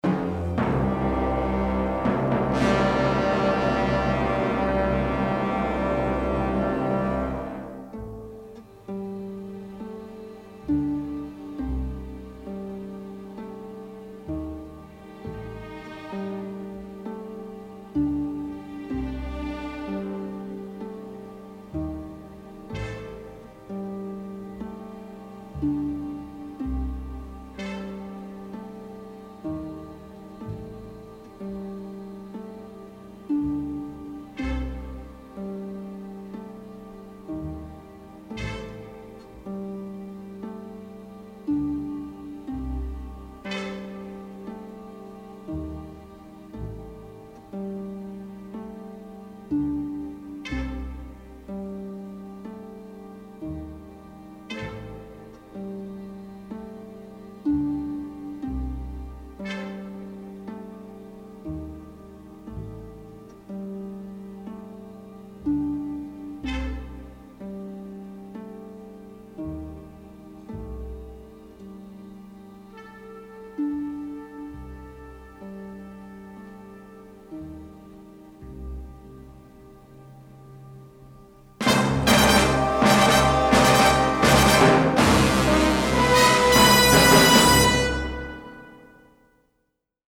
orchestral score